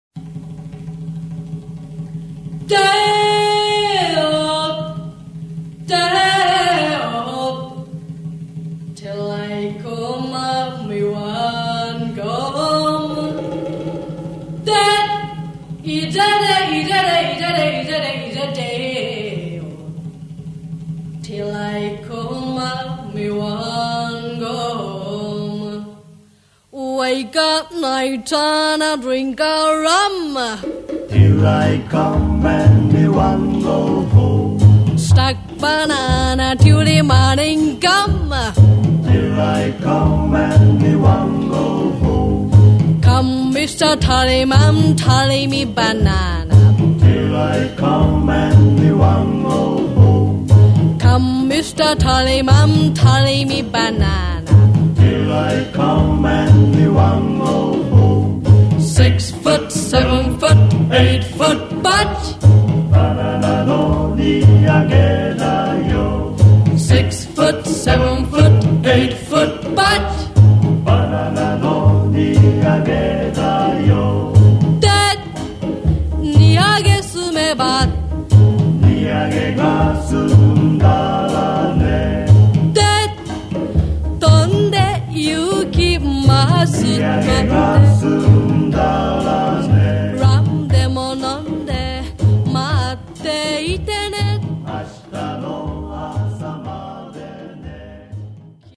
これが230万枚を記録した“ジャマイカン・ビート歌謡”の源流。
その澄み切ったサウンドには、時代を超える生命力が宿っている。